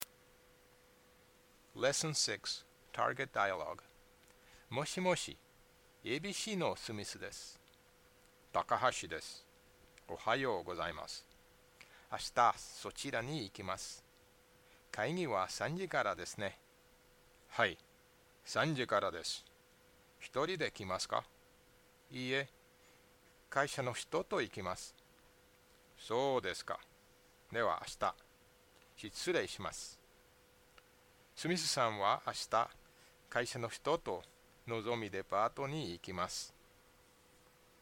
Japanese JFBP L6 dialogue